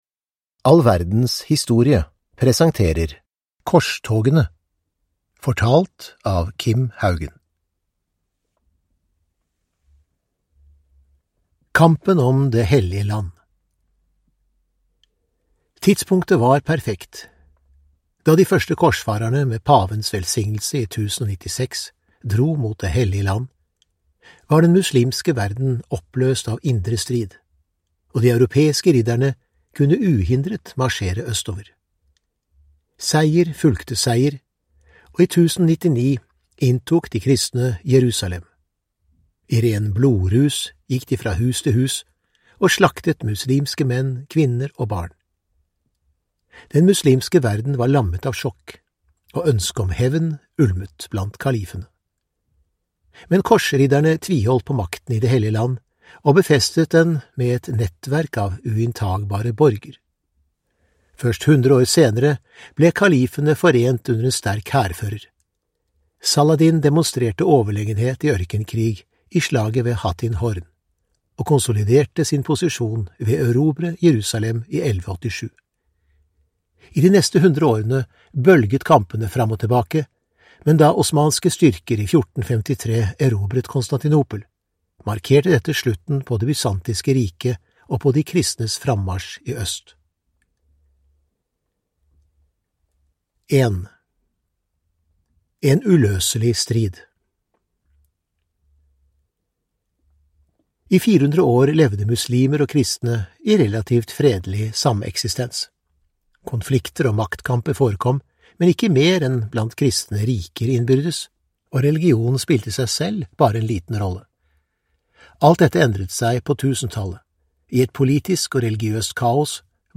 Korstogene (ljudbok) av All verdens historie